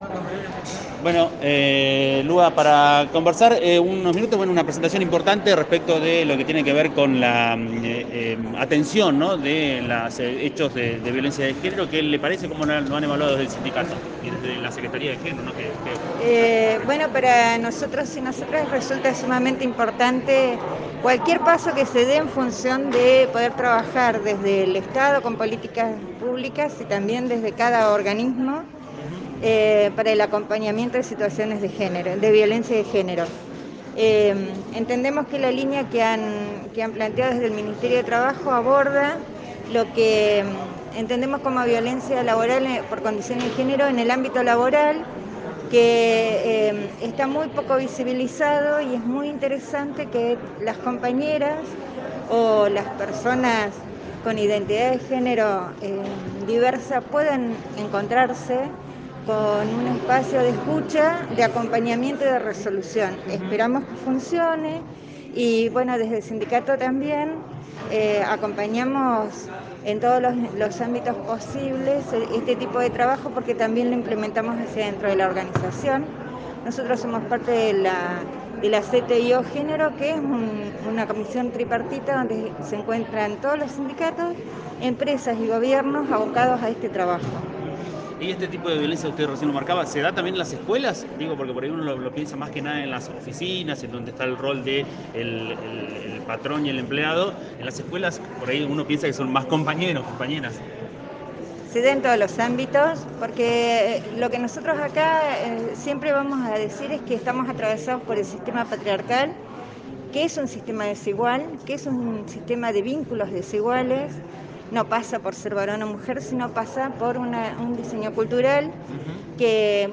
Entrevistas en radio “Encuentro”
Gral. Roca – Fiske Menuco, 1 de octubre de 2021.